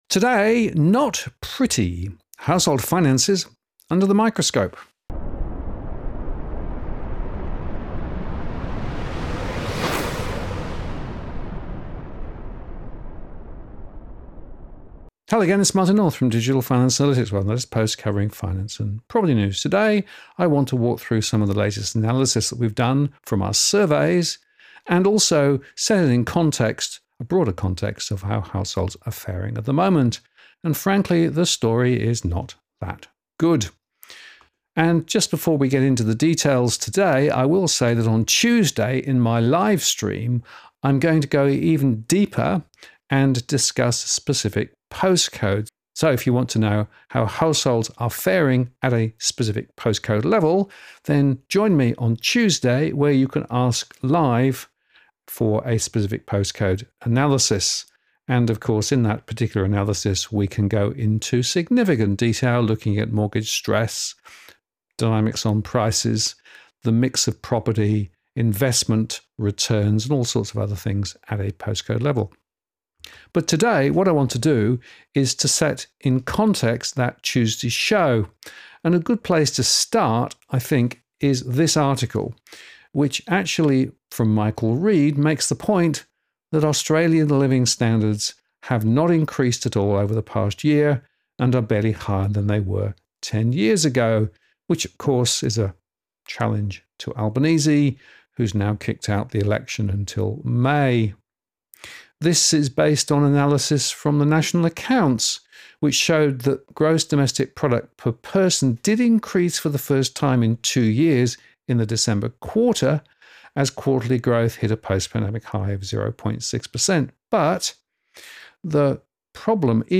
DFA Live Replay Q&A: A Deep Dive On Post Codes Feeling The Pinch